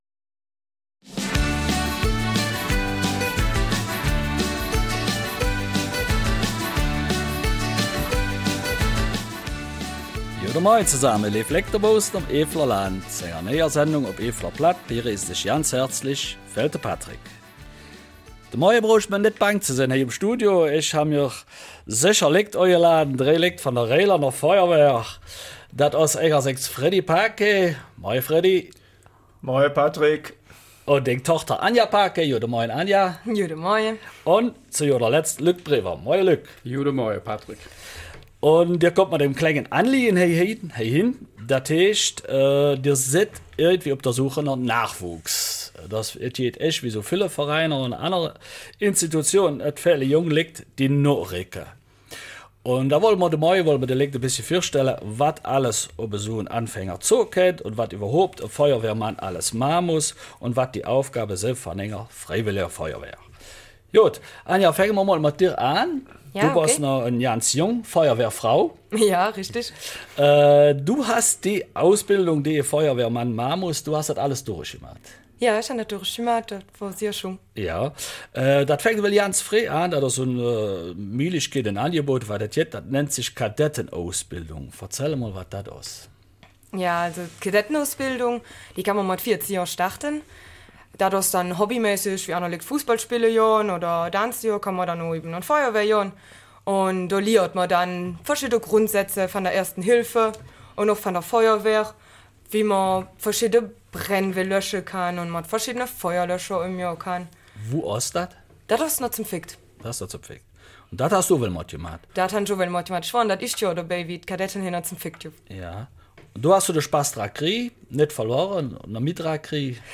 Eifeler Mundart: Freiwillige Feuerwehr Burg-Reuland